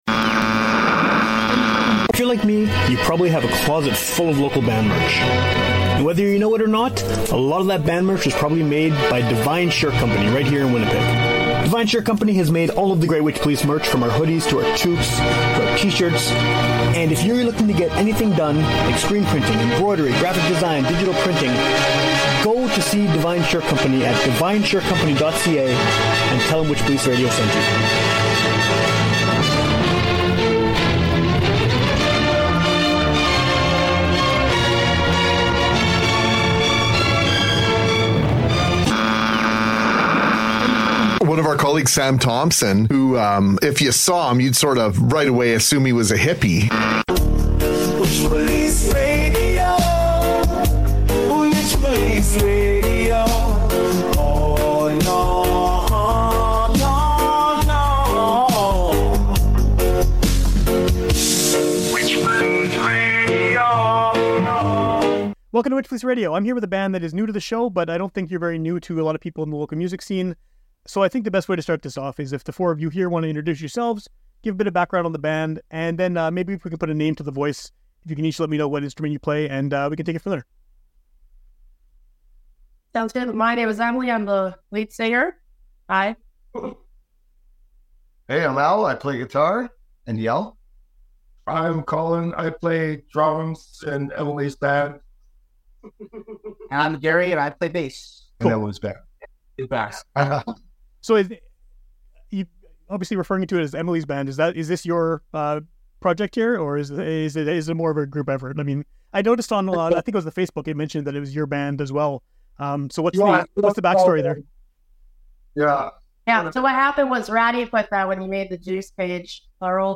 Recorded an interview with local punx Jus D'Orange!Tune in for our conversation about French food labels, musical simplicity, band families, and much more...
(As always, when I'm talking to a big group of people who are all in the same room, the audio quality can sometimes suffer, but it certainly doesn't take away from the quality of the conversation).